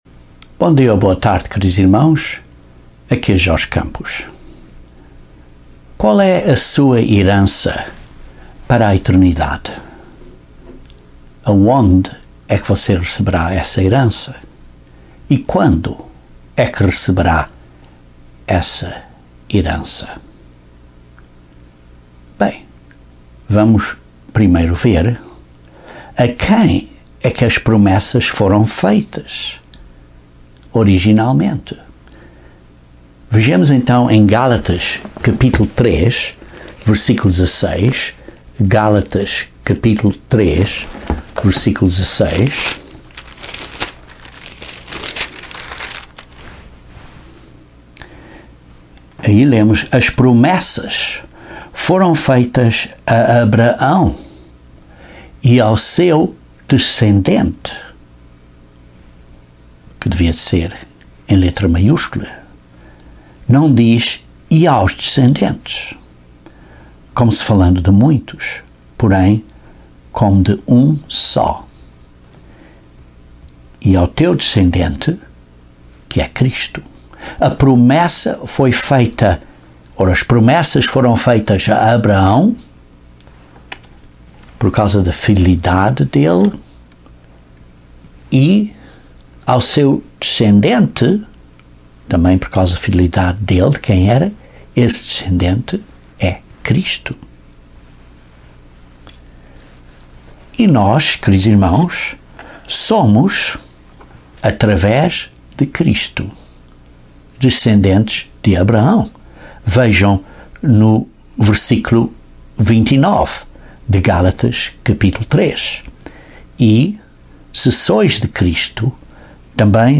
Este sermão explica a quem a promessa da herança foi dada, o que é, e quando será dada.